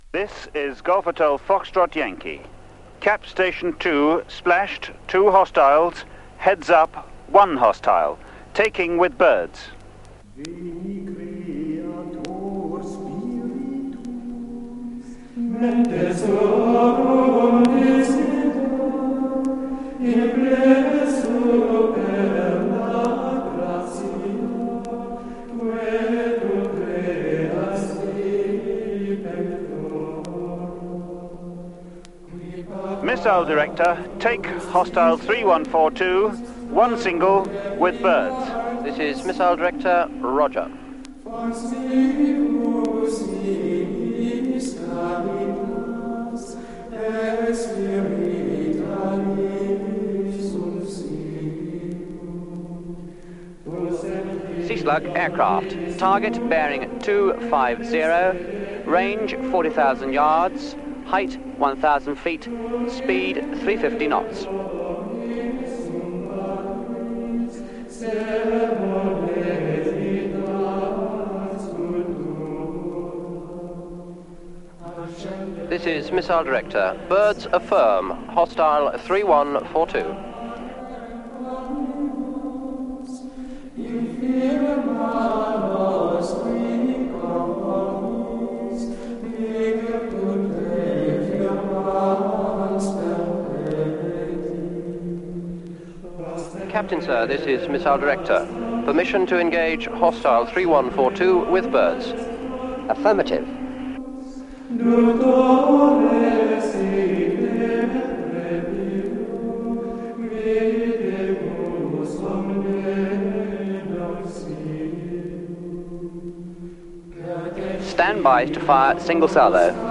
Missile - Click here for the Holy Hand-Grenade style Sea Cat Missle